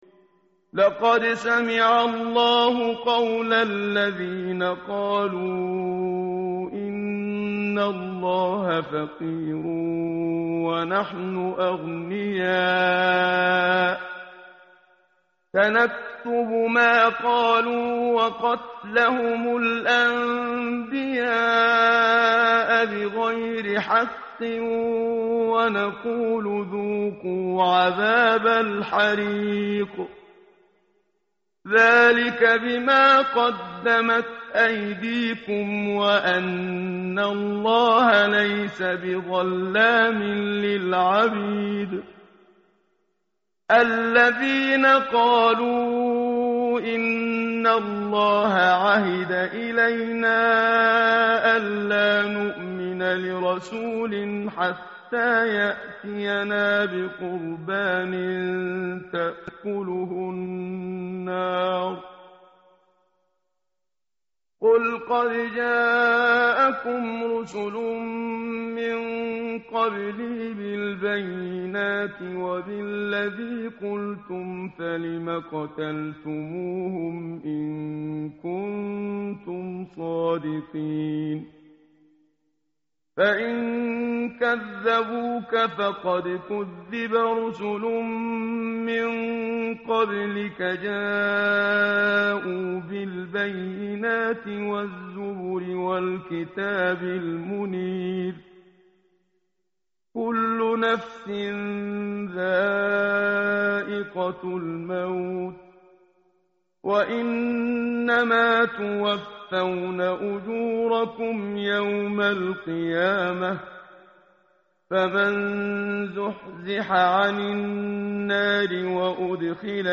tartil_menshavi_page_074.mp3